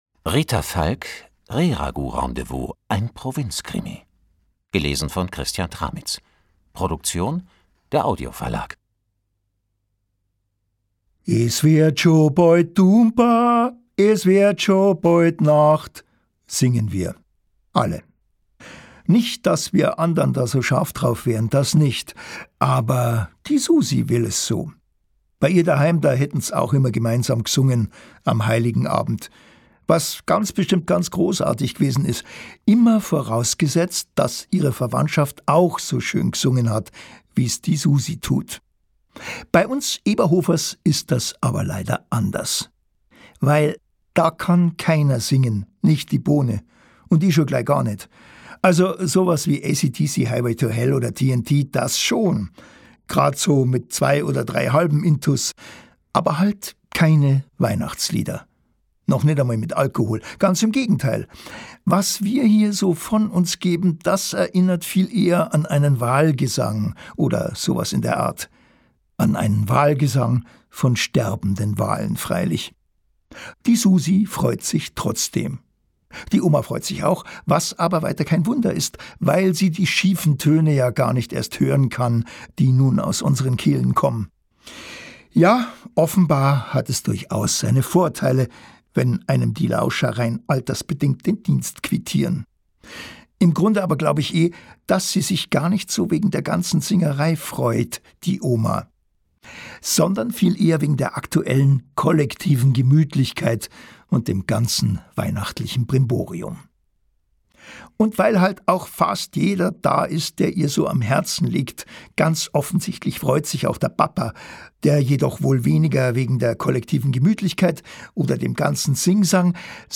Christian Tramitz (Sprecher)
Ungekürzte Lesung